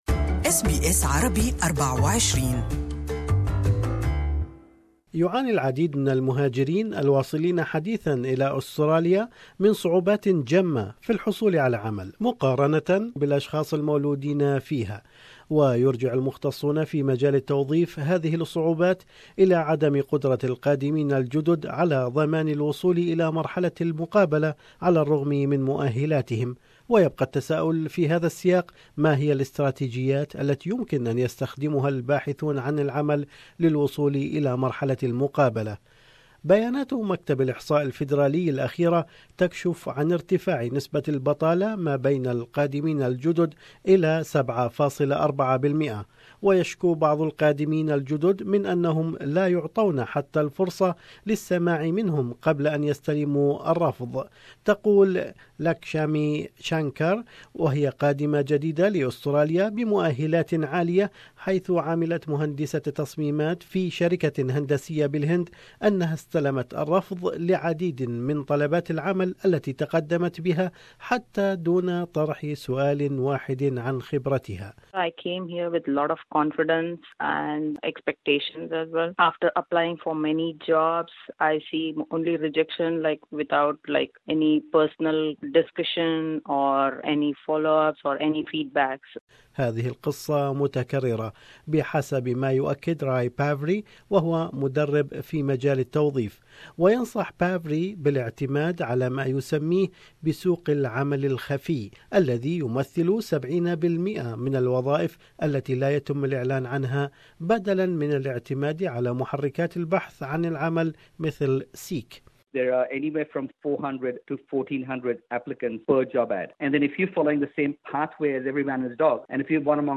التقرير التالي يسلط الضوء على افضل الطرق في الوصول مرحلة المقابلة وكيفية التعامل مع لجنة القبول.